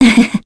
Scarlet-vox-Happy1_kr.wav